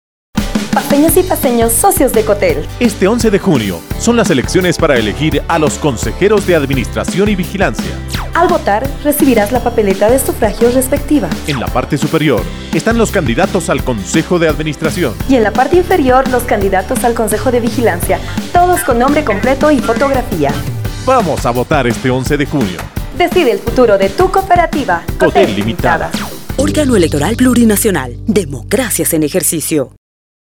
También se realizó la producción de cuñas informativas que se difunden en medios de comunicación que alcanzan a las cinco circunscripciones que fueron convocadas:
cuna_1_cotel.mp3